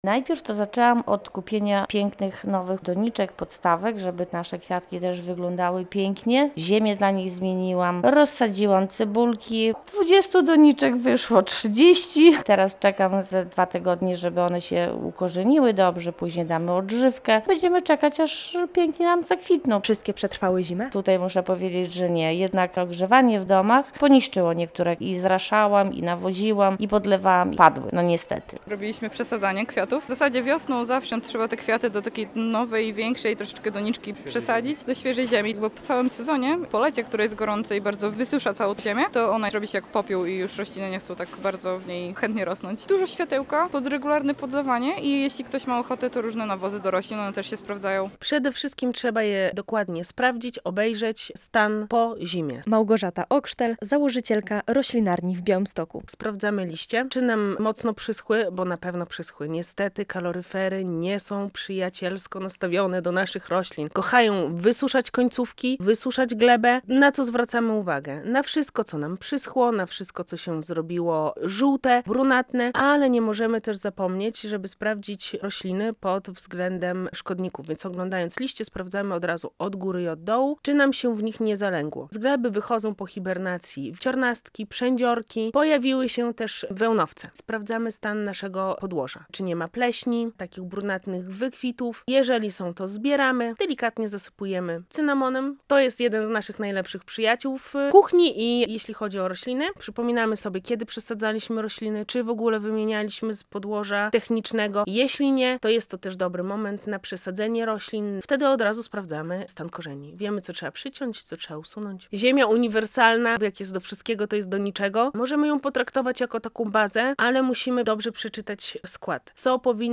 Czy kwiaty na parapecie też potrzebują wiosennego przebudzenia? Pytamy białostoczan, jak w ich domach wygląda wiosenna pielęgnacja roślin.